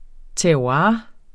Udtale [ tæɐ̯ˈwɑː ]